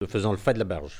Localisation Saint-Gervais
Catégorie Locution